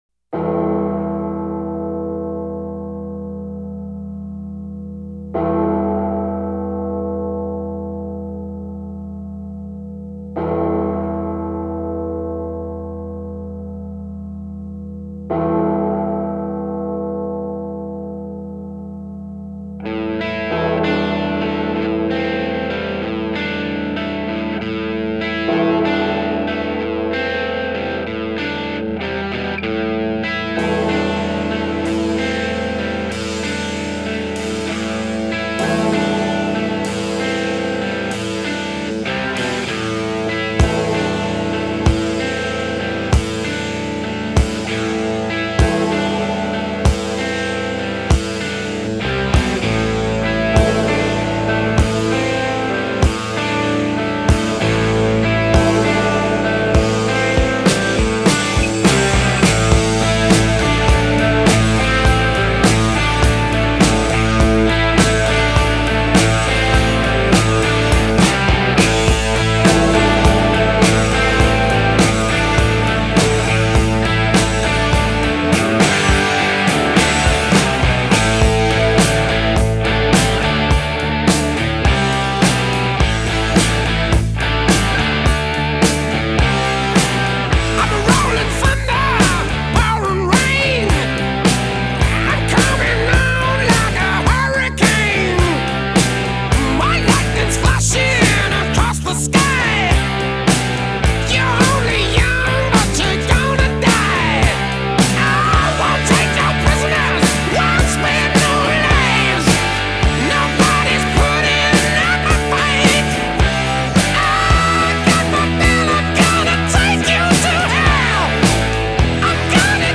Жанр: Hard Rock, Heavy Metal